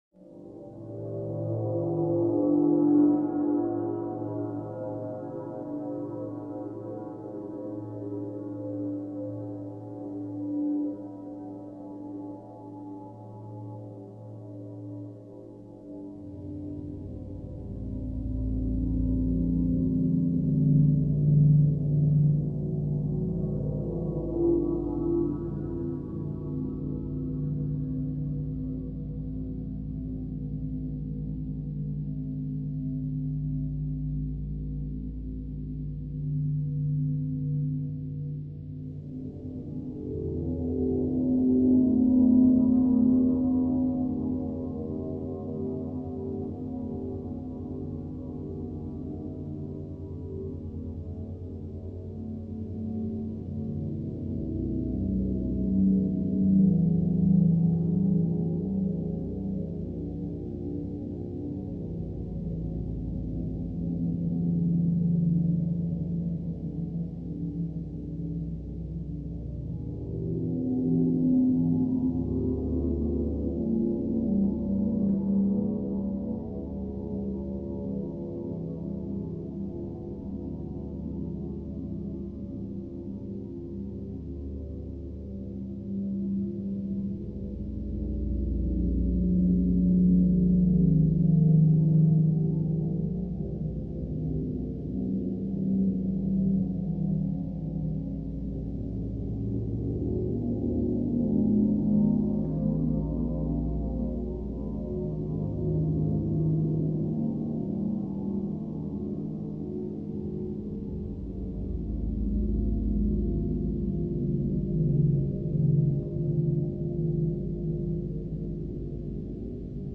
horror soundscapes